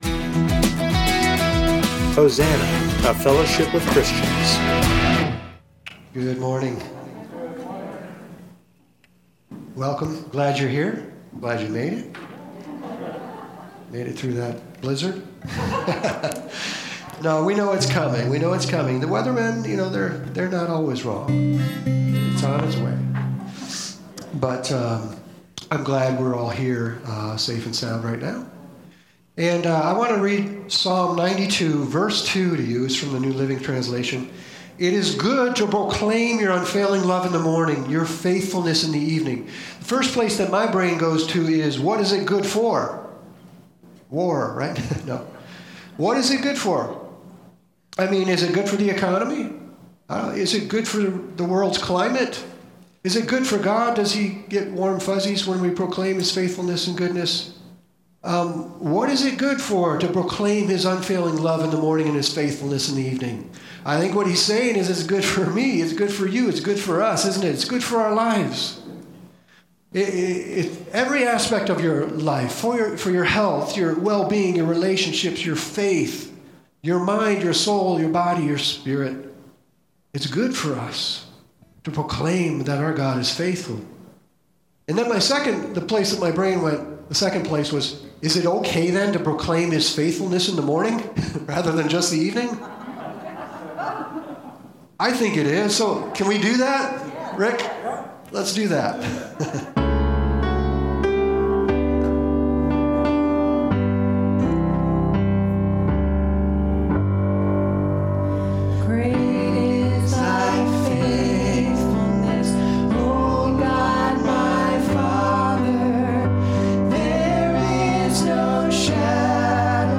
Genre: Spoken Word.